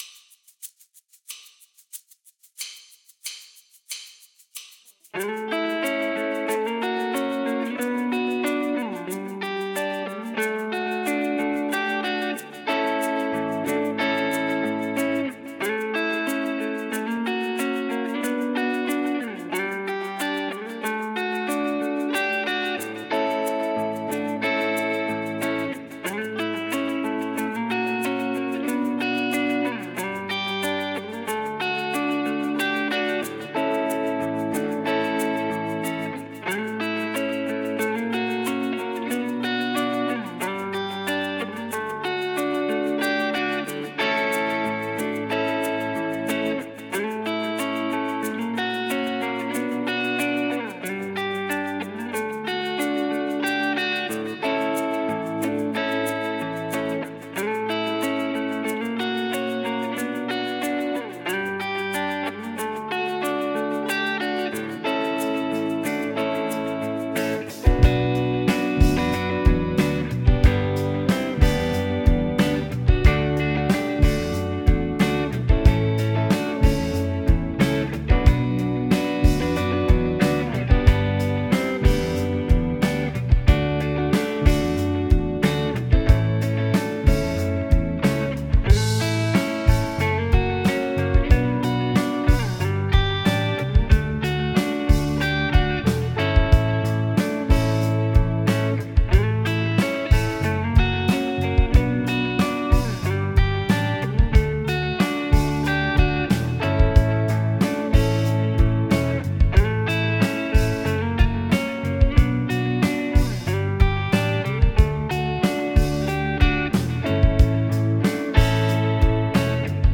BPM : 92
Tuning : E
Without vocals